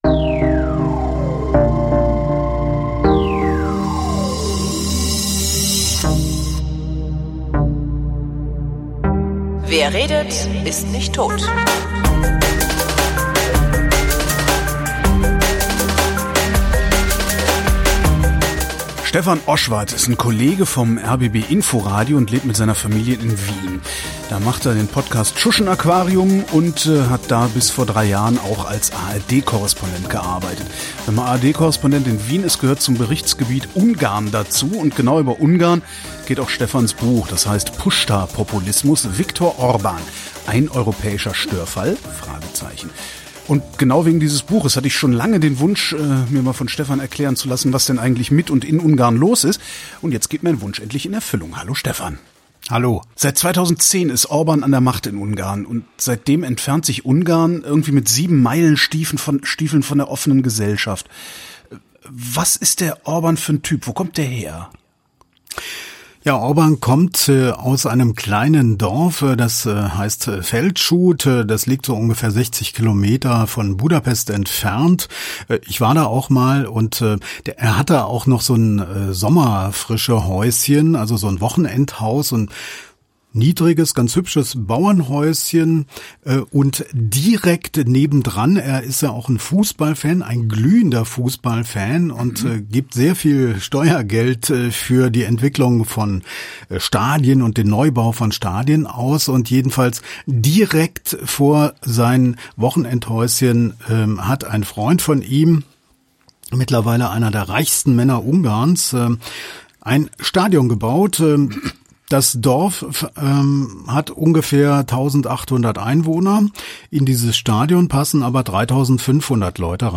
Ich habe mit ihm geredet, um ein wenig besser zu verstehen, wie es dazu kommen konnte, dass Ungarn sich 30 Jahre nach dem Fall des eisernen Vorhangs in Richtung Autokratie bewegt.